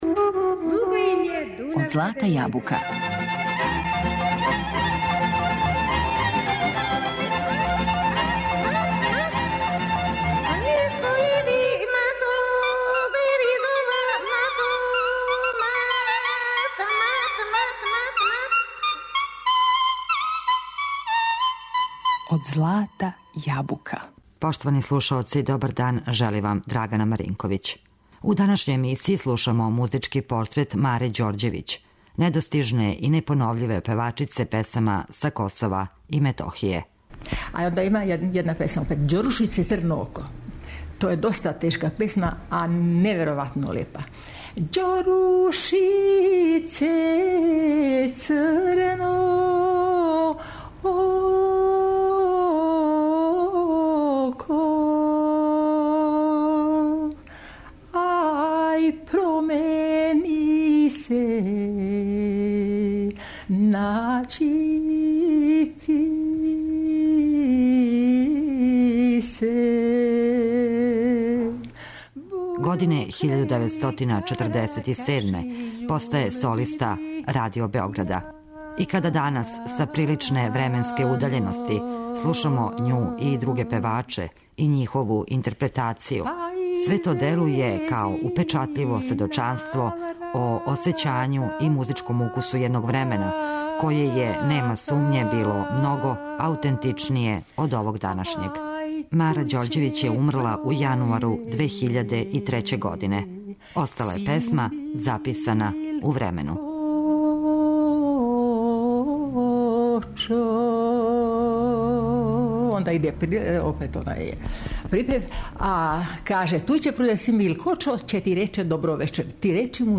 У данашњој емисији слушамо музики портрет уметнице Маре Ђорђевић, недостижне и непоновљиве певачице песама са Косова и Метохије.